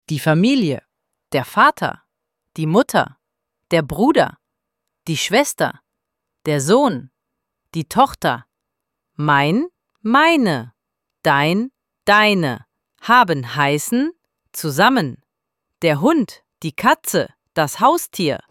Izgovor – RIJEČI I IZRAZI
ElevenLabs_Text_to_Speech_audio-40.mp3